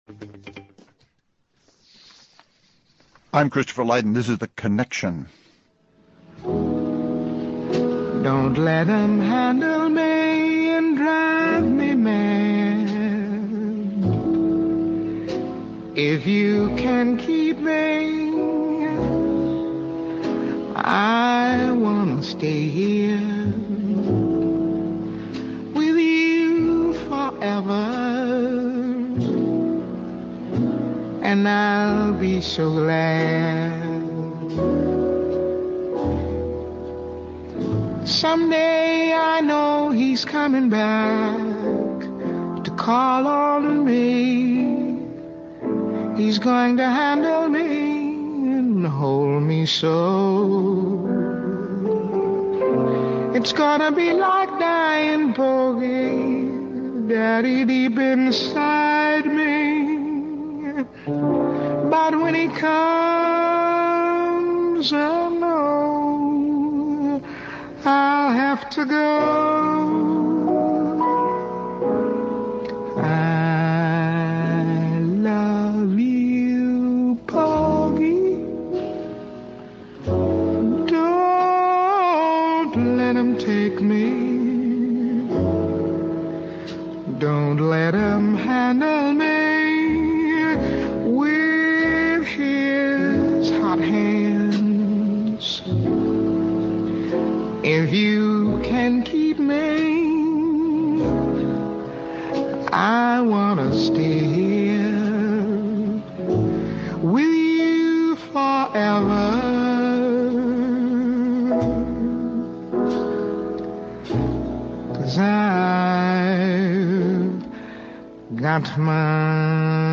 The husky voice that crosses from blues and jazz to church music and show tunes is freighted with experience, politics and passion.